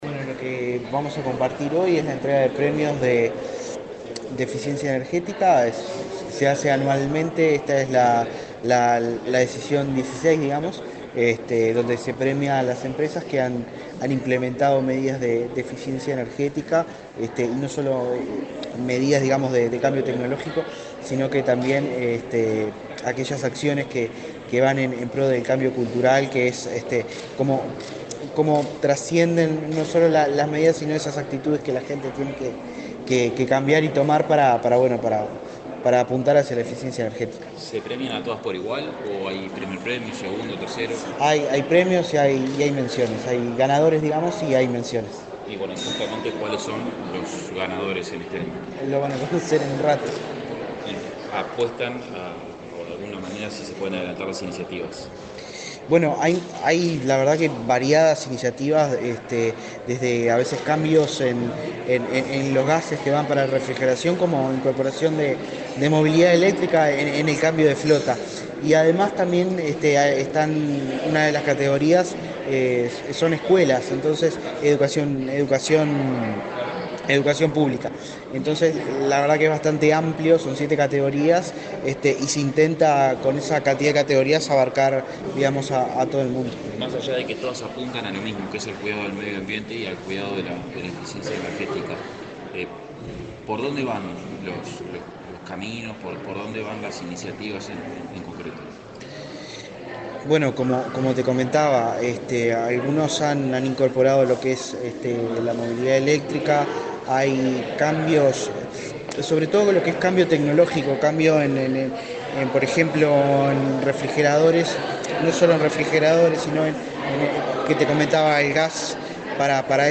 Declaraciones del director nacional de Energía, Christian Nieves
Este viernes 25 en la Torre Ejecutiva, el director nacional de Energía, Christian Nieves, dialogó con la prensa, antes de participar en la ceremonia